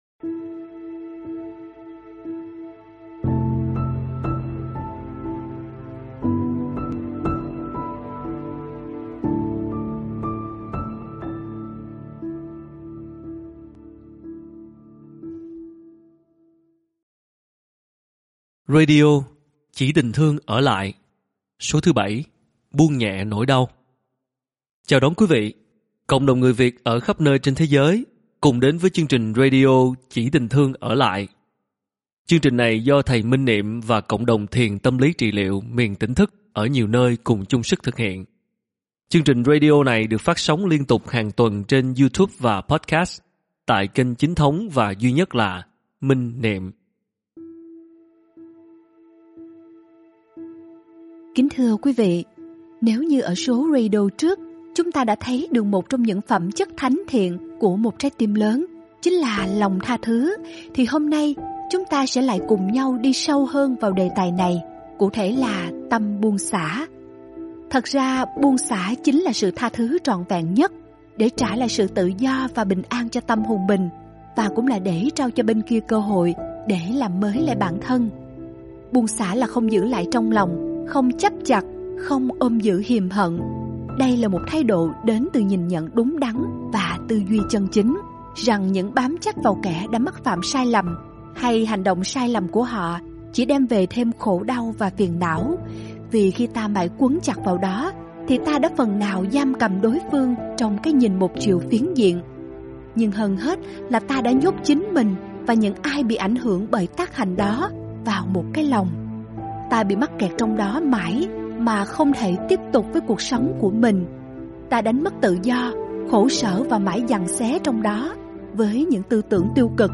Mời quý phật tử nghe Radio Chỉ tình thương ở lại – Số 7: Buông nhẹ nỗi đau do thiền sư Thích Minh Niệm giảng